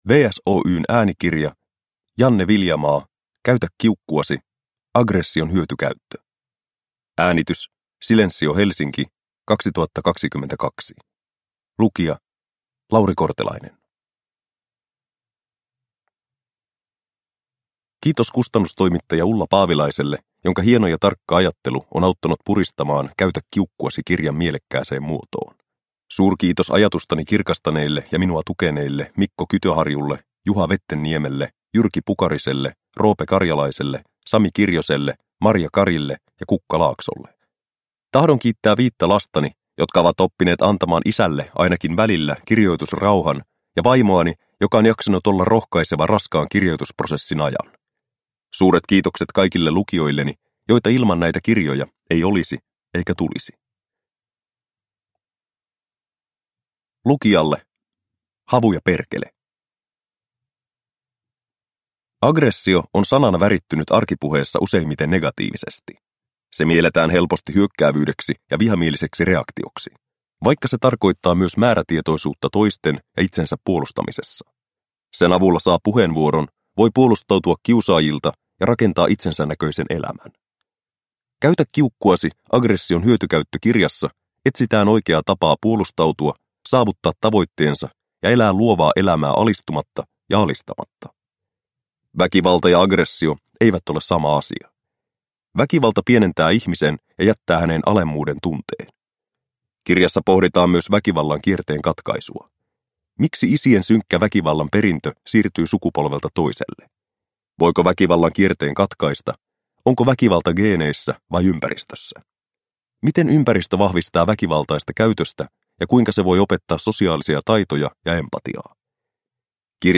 Käytä kiukkuasi – Ljudbok – Laddas ner